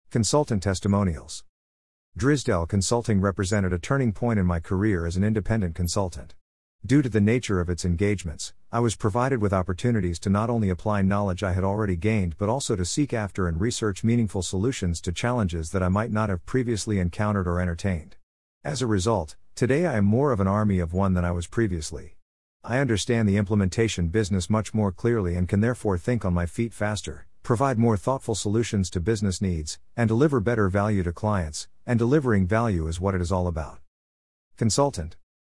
easytts_audio_Consultant-Testimonials1.mp3